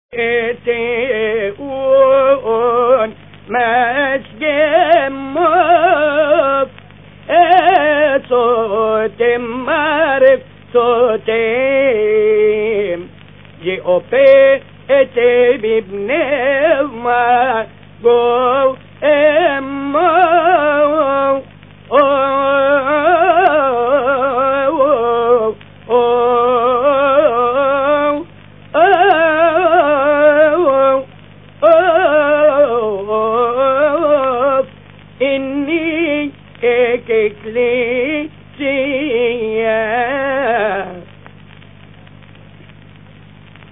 مكتبة الالحان | لحن من له اذنان للسمع - في ايتي أووأون ماشج